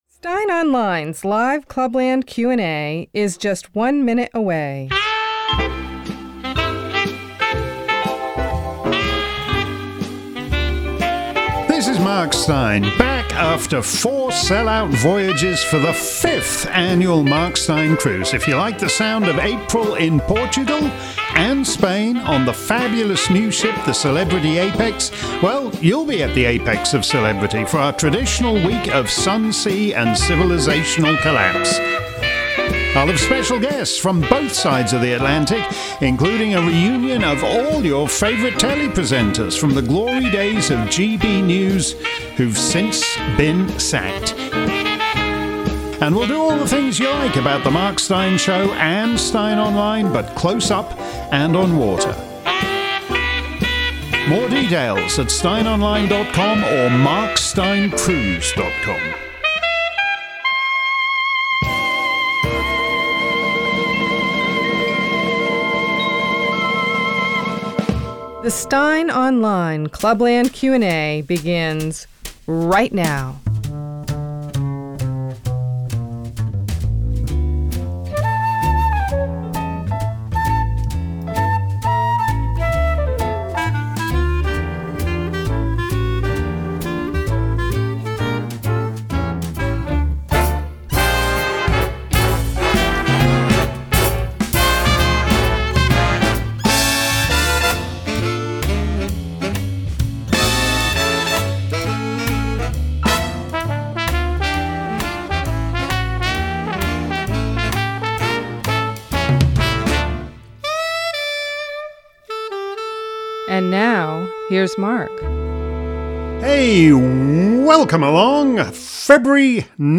If you missed today's edition of Steyn's Clubland Q&A live around the planet, here's the action replay. This week's show focused first on the state of play in the Ukraine war and then turned to other topics.